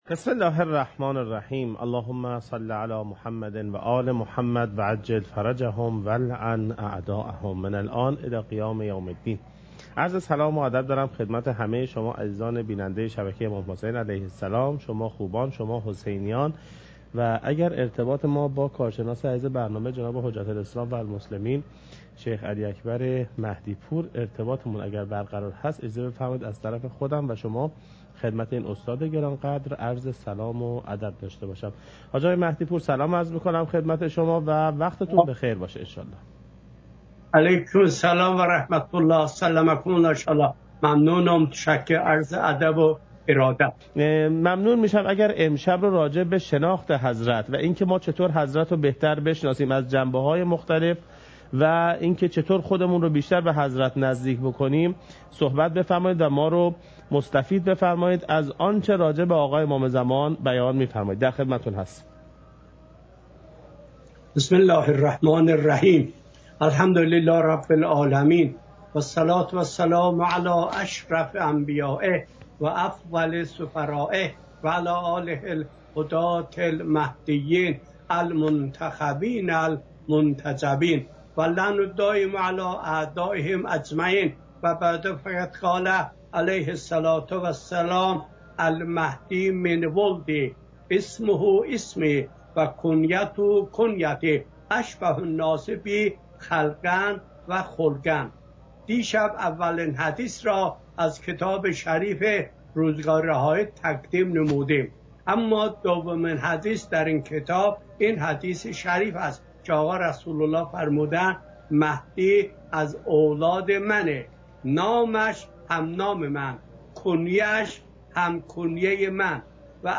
حجم: 7.43 MB | زمان: 31:07 | تاریخ: 1441هـ.ق | مکان: کربلا